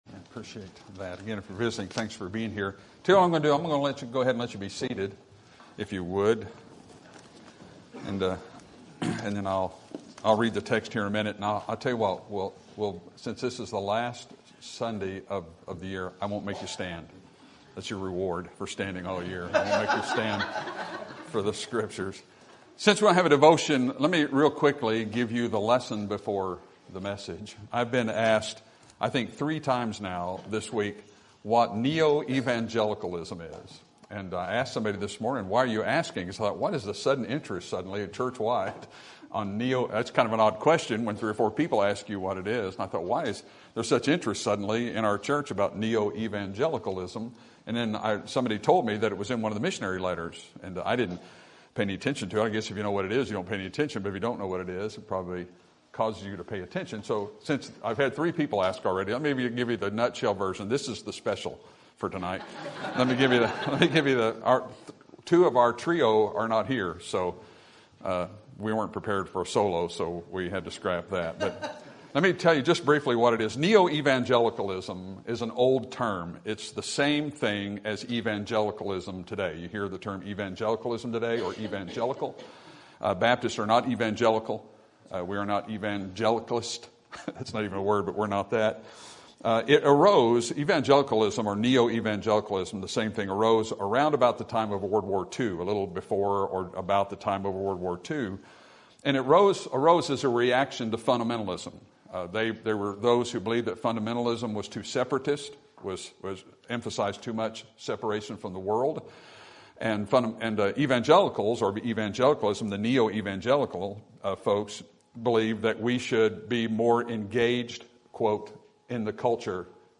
Sermon Topic: Book of 1 Peter Sermon Type: Series Sermon Audio: Sermon download: Download (26.36 MB) Sermon Tags: 1 Peter Husband Wife Compliment